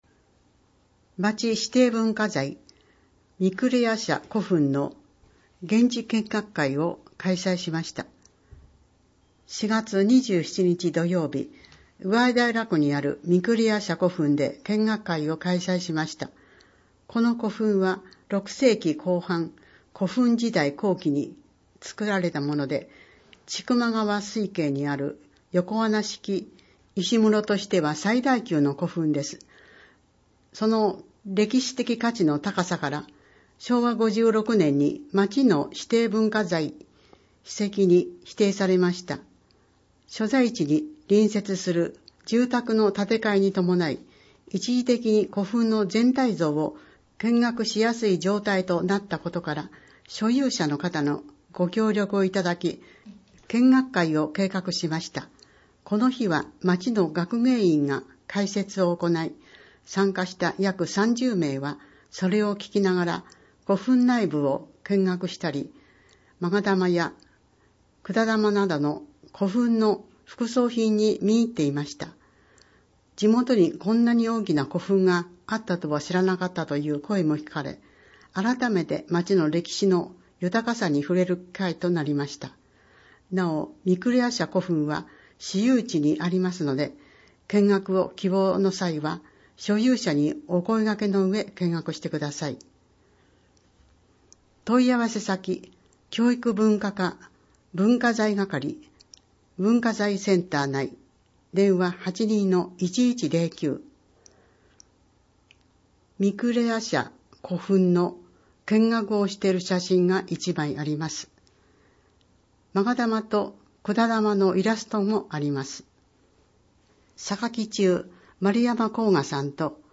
また、音訳ボランティアサークルおとわの会のみなさんによる広報の音訳版のダウンロードもご利用ください。
（PDF文書）   広報音訳版ダウンロード（制作：おとわの会）